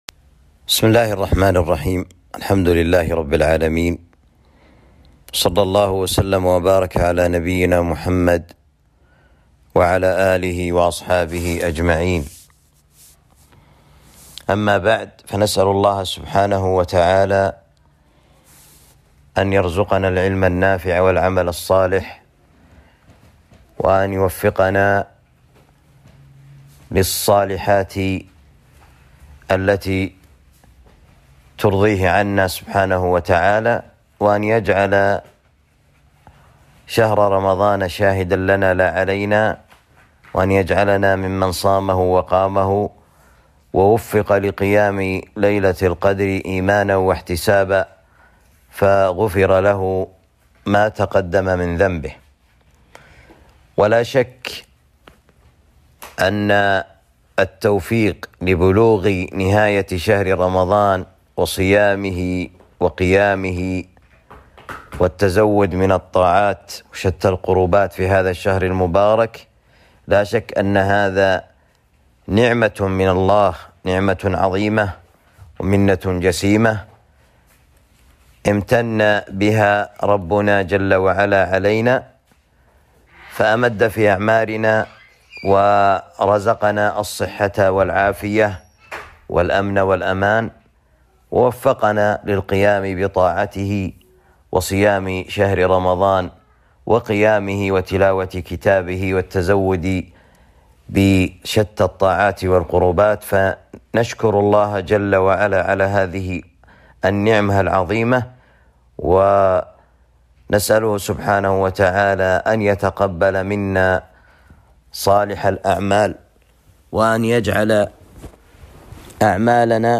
محاضرة